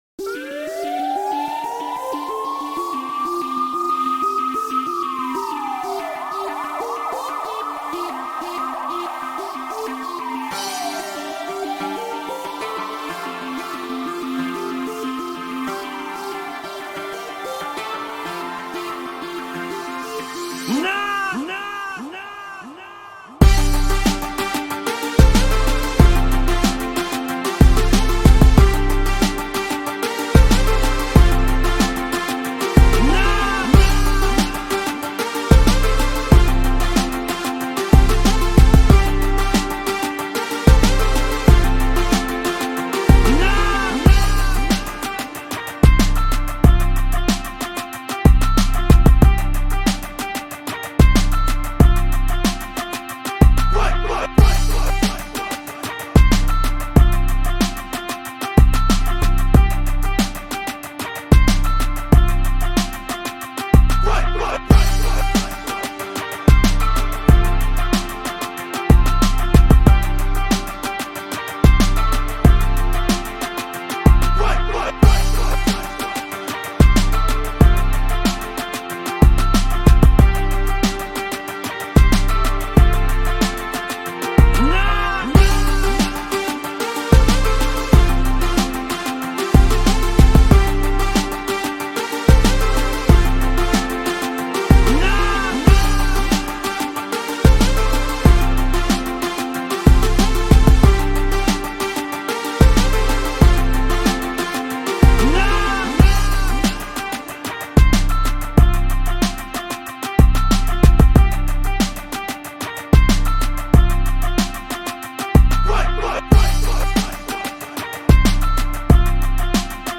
DANGEROUS_Motivational_Guitar_Instmental_
DANGEROUS_Motivational_Guitar_Instmental_.mp3